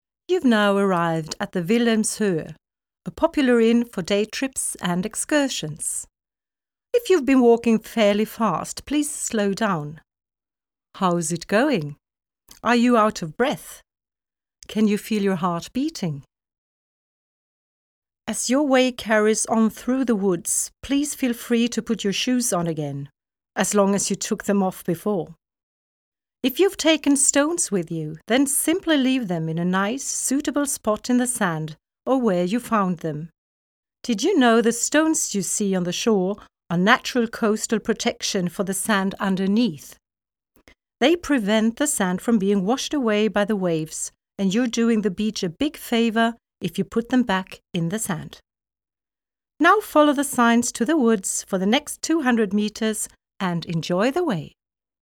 Audioguide
Responsible for the sound recordings: Sound studio at Stralsund University of Applied Sciences.